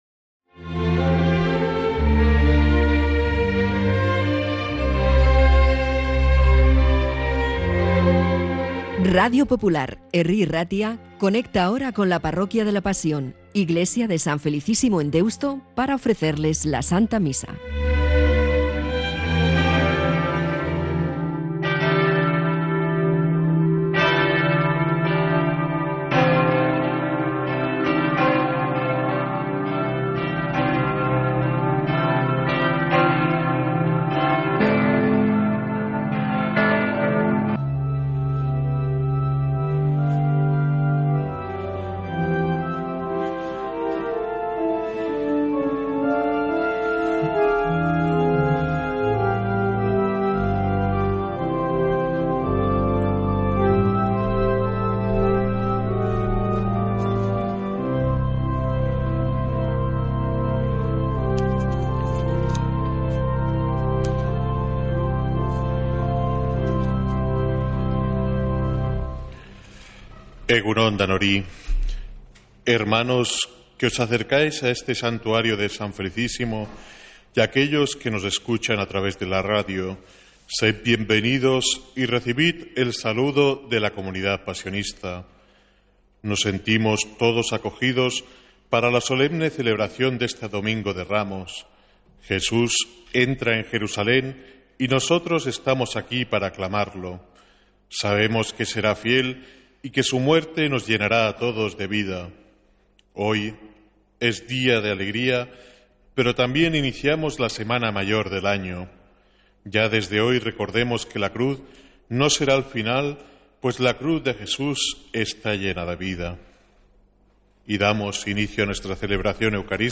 Santa Misa desde San Felicísimo en Deusto, domingo 13 de abril